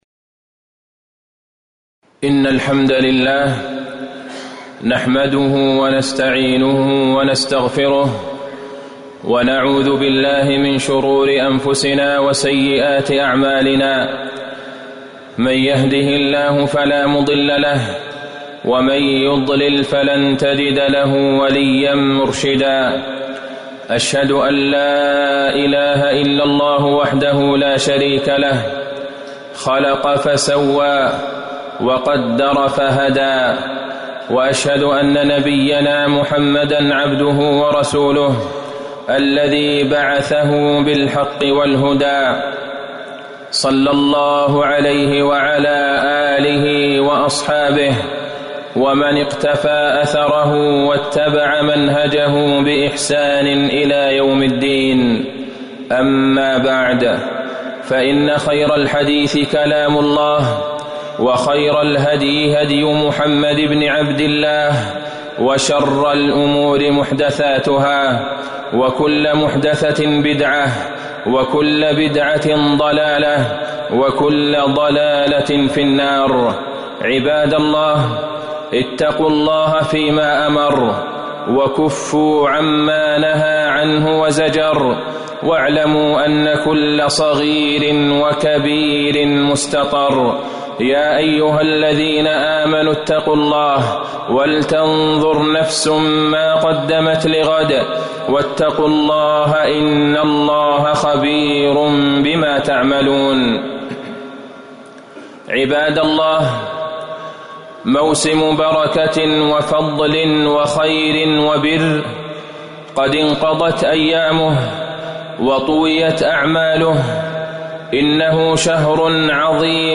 تاريخ النشر ١١ شوال ١٤٤٠ هـ المكان: المسجد النبوي الشيخ: فضيلة الشيخ د. عبدالله بن عبدالرحمن البعيجان فضيلة الشيخ د. عبدالله بن عبدالرحمن البعيجان الحث على التوبة والاستقامة بعد رمضان